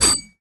poly_shoot_crystal.wav